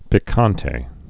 (pĭ-käntā)